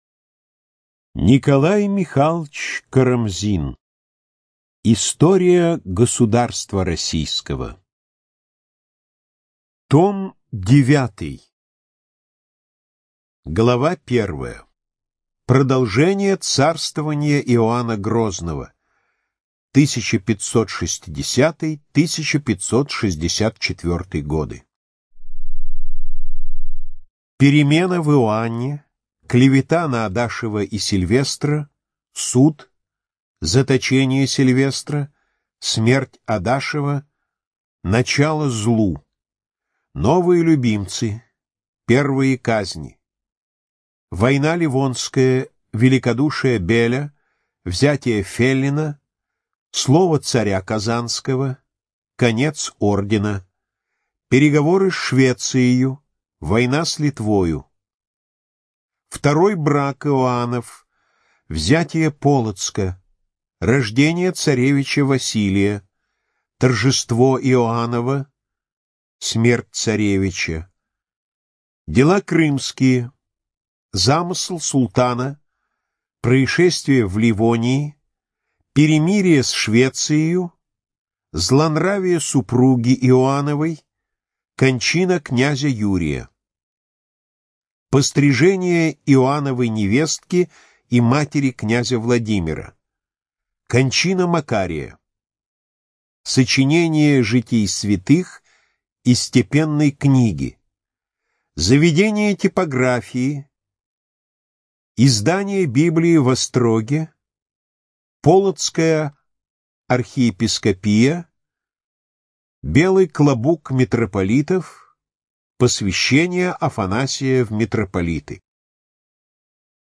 Студия звукозаписиАрдис